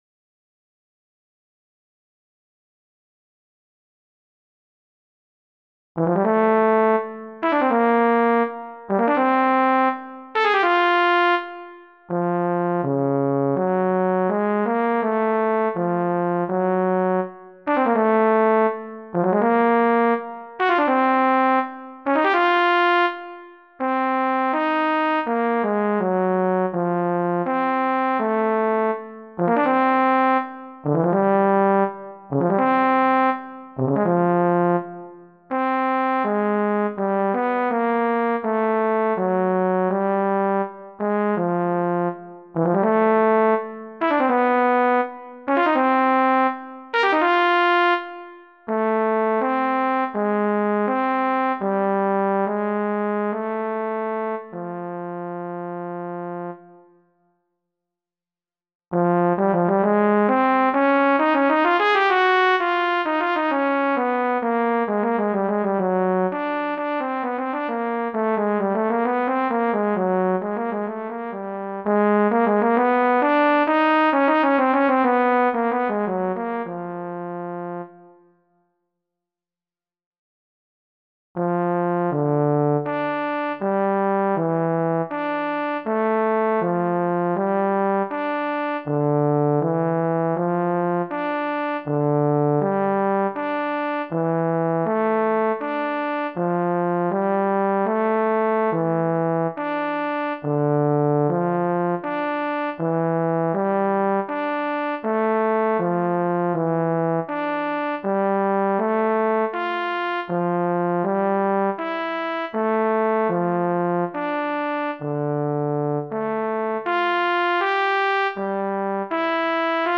Pour saxhorn alto ou autre cuivre solo, sans accompagnement.